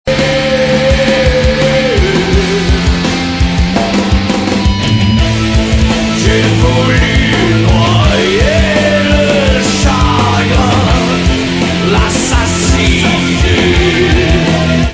métal influences diverses Bioul écoutez l'extrait 1